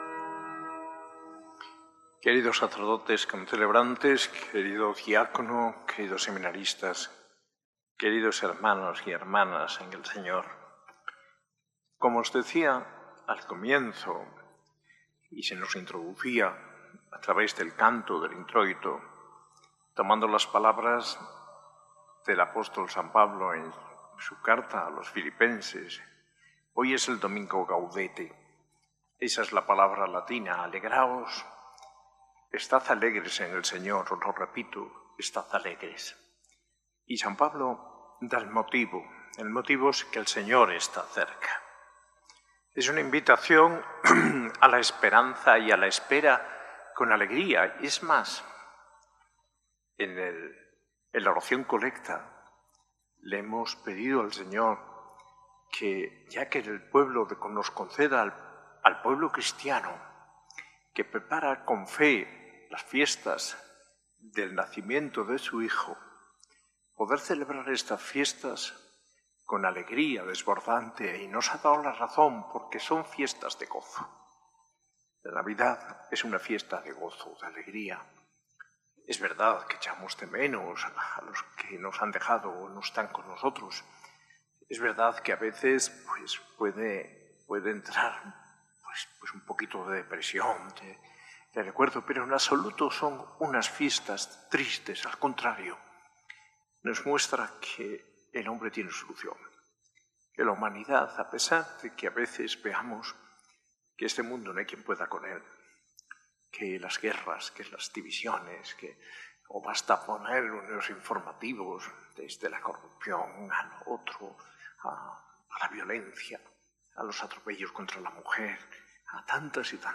Homilía de Mons. José María Gil Tamayo, arzobispo de Granada, en la Eucaristía del III Domingo de Adviento, celebrada el 14 de diciembre de 2025, en la S.A.I Catedral.